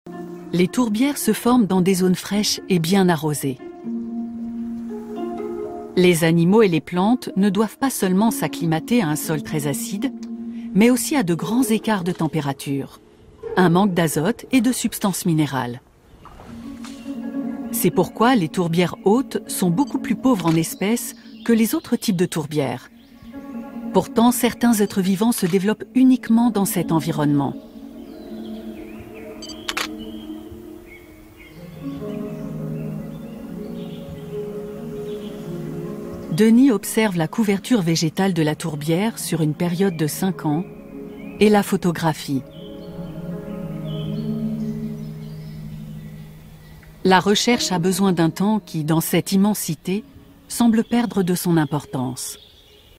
Narration documentaire Arte
Voix off